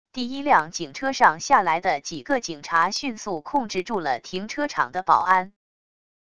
第一辆警车上下来的几个警察迅速控制住了停车场的保安wav音频生成系统WAV Audio Player